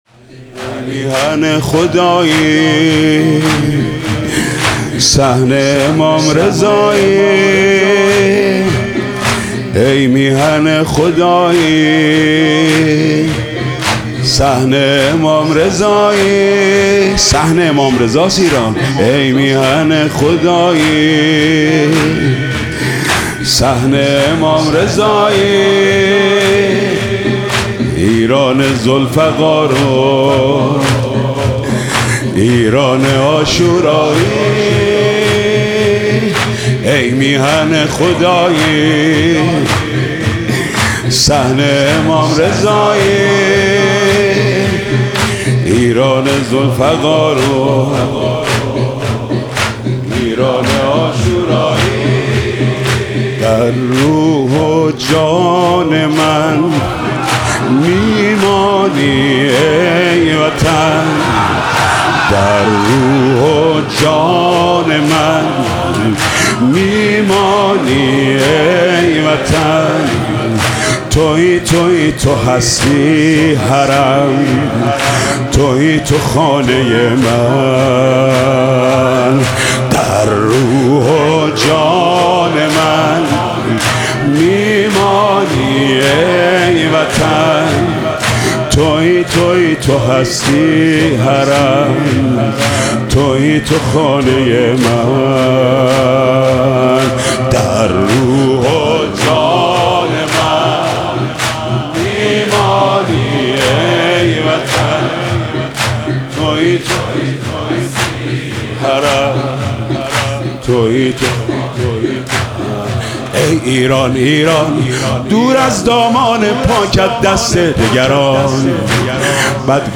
مداحی جدید
مراسم محرم 1404